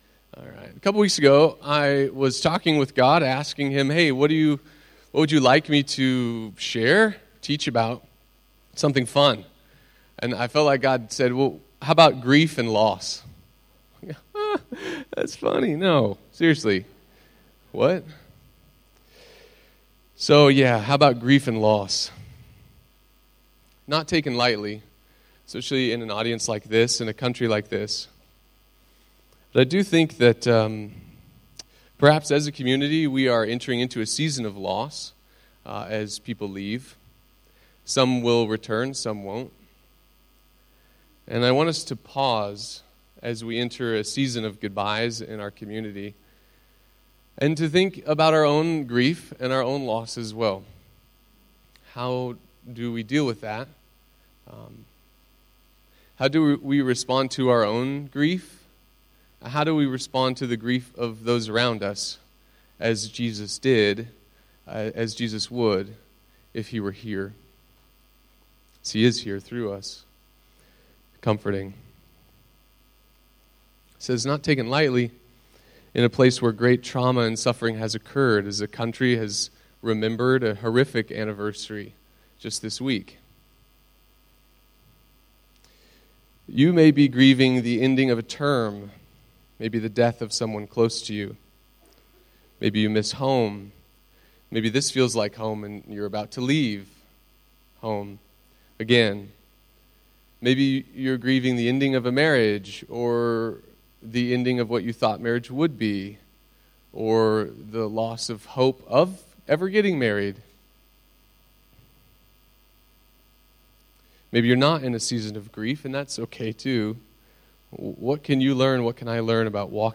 Last Sunday I had the privilege of preaching at the ICF here in Phnom Penh, Cambodia.